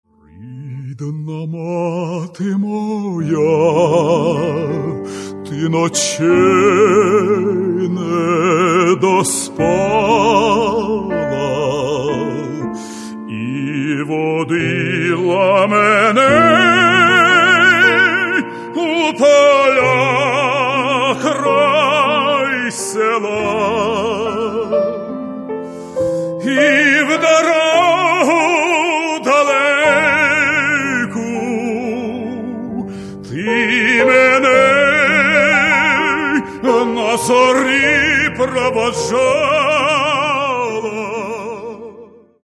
Українські Романси.